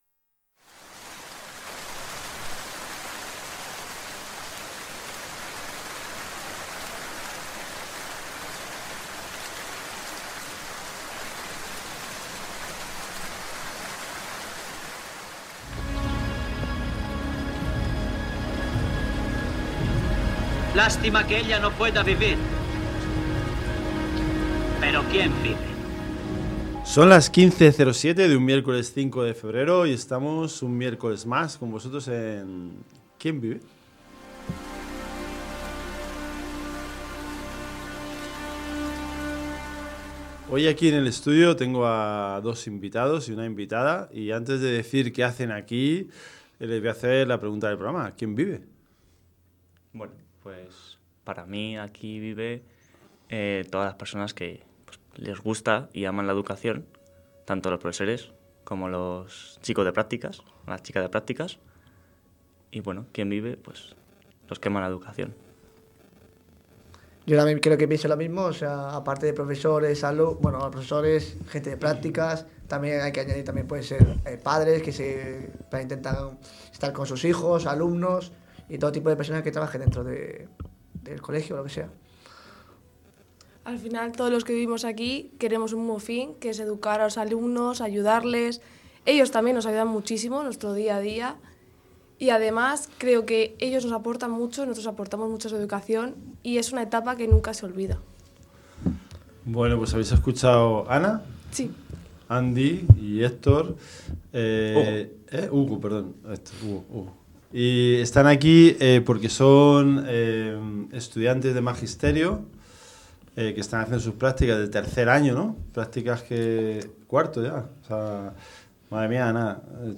En este episodio, nos adentramos en el mundo de la formación docente con una conversación especial.